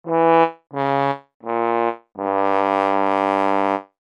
epic_fail.mp3